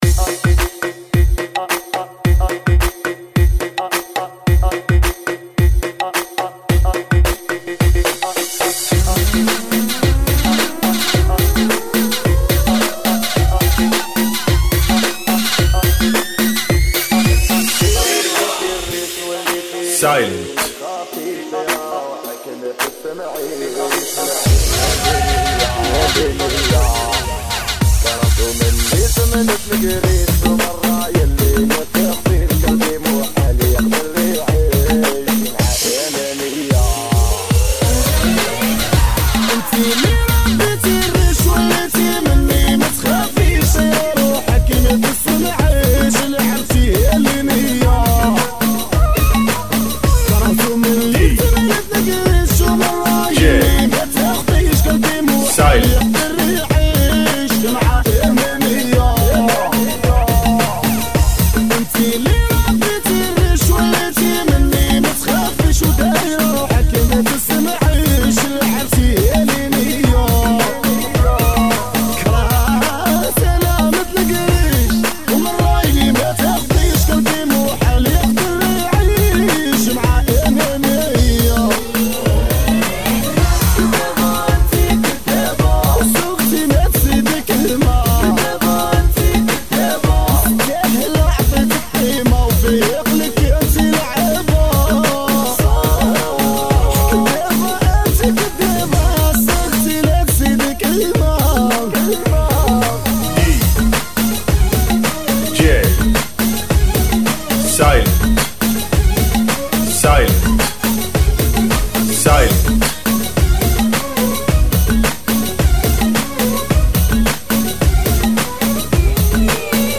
[ 68 BPM ]